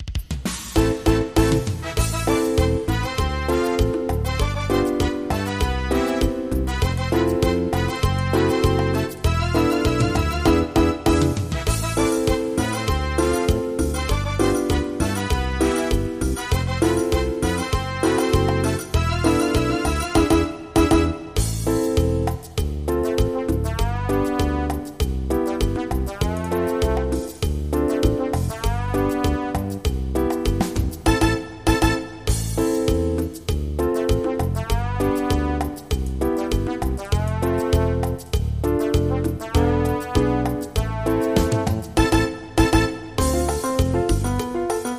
MIDI · Karaoke
sin letra